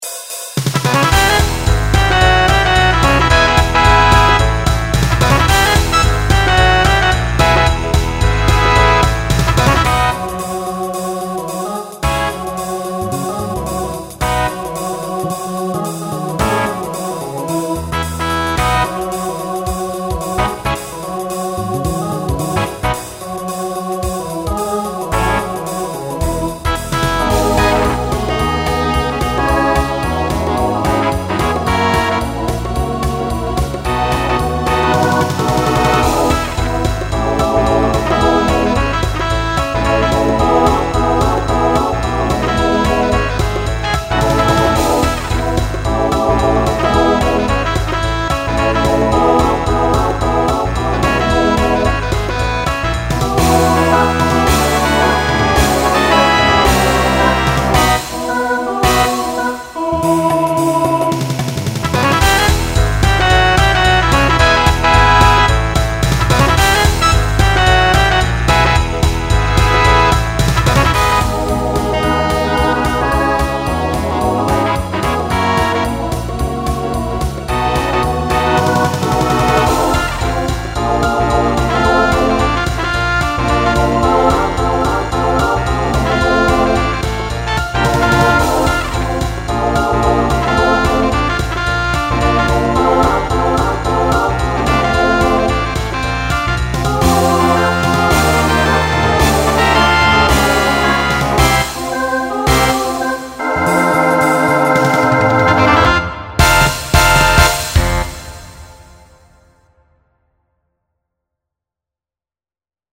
Genre Swing/Jazz Instrumental combo
Transition Voicing SATB